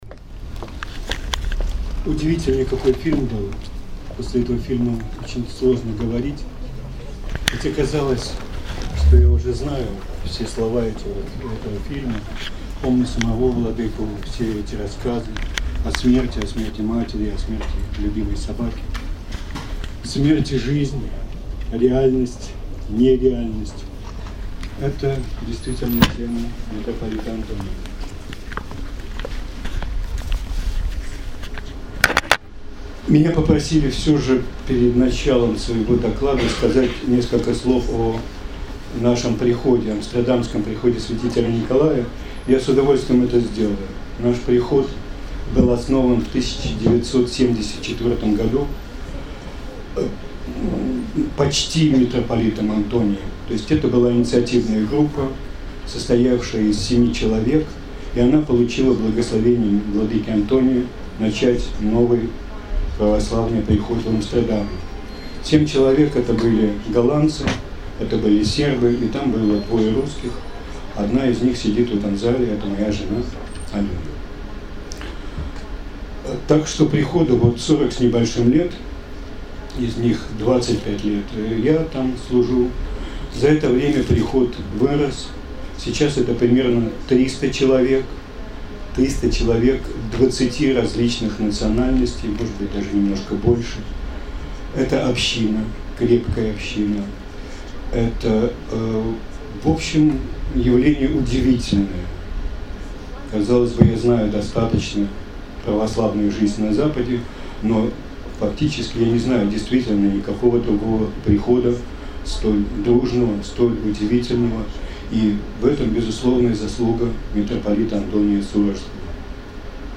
Митрополит Антоний и Мераб Мамардашвили. Доклад
на V международной Конференции, посвященной наследию митрополита Антония Сурожского (18-20 сентября 2015 г.)